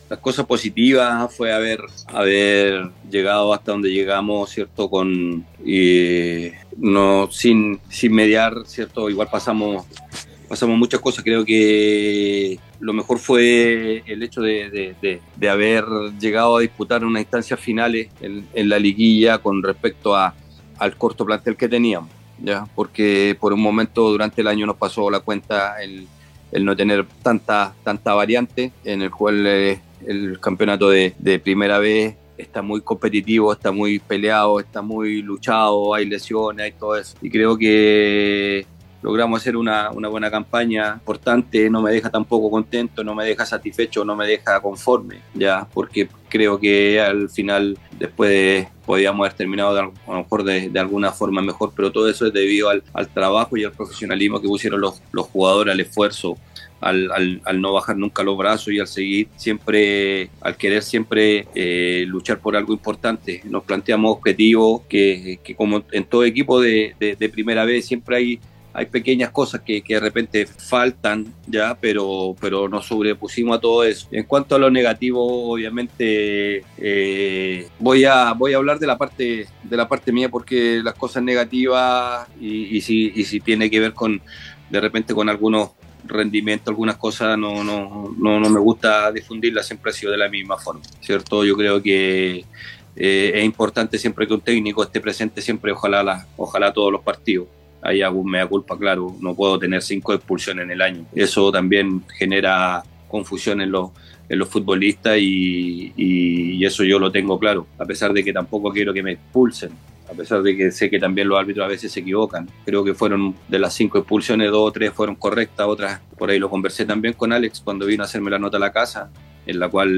En diálogo con Puntero Izquierdo